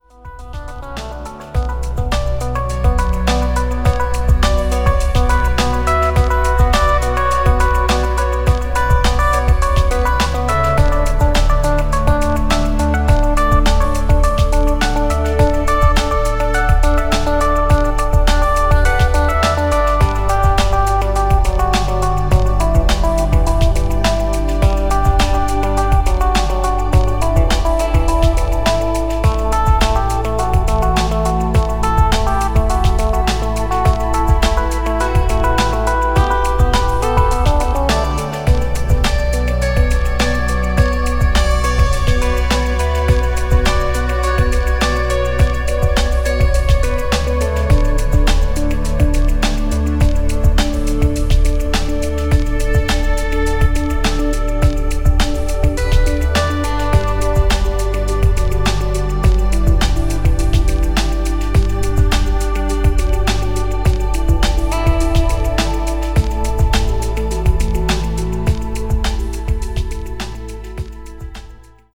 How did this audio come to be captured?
The audio is given added stereo width in Cubase.